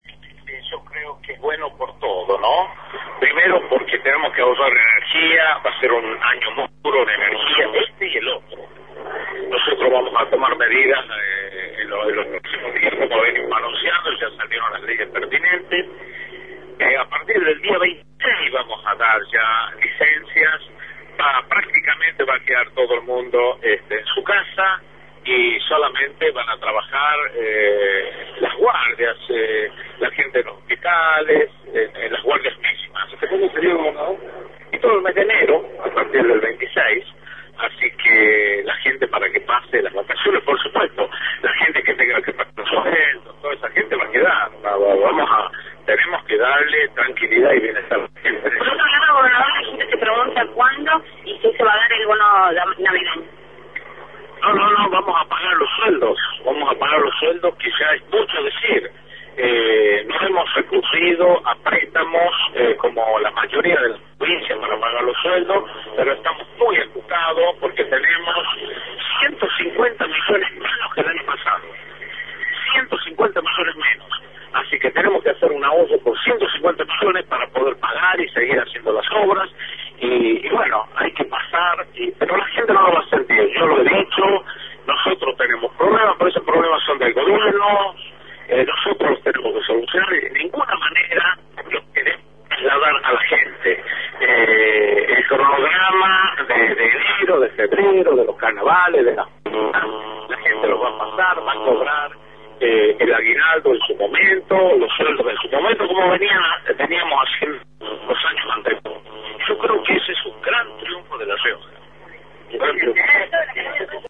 Luis Beder Herrera, gobernador de La Rioja, por Radio Independiente